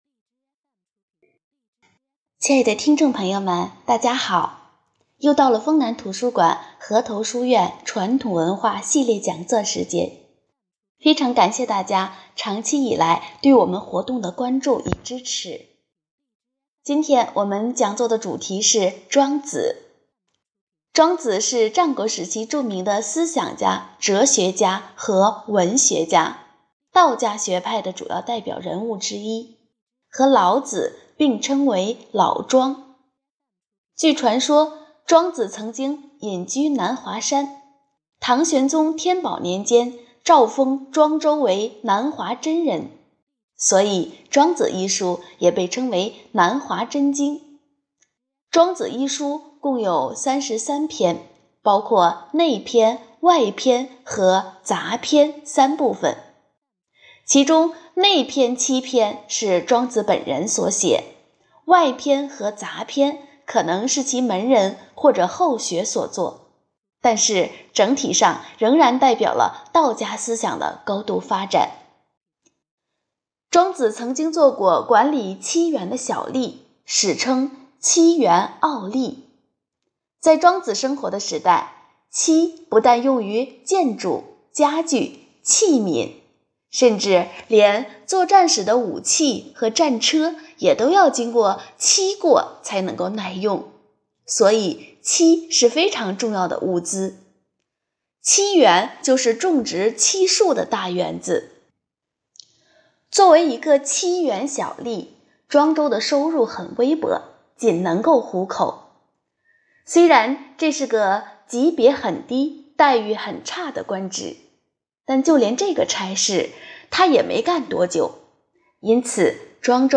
活动预约 - 【讲座】 丰南图书馆讲给孩子的中国文学经典——庄周与《庄子》